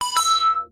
It's a square wave. It's two note. A tiny spot of reverb and bit-crushing and I was done!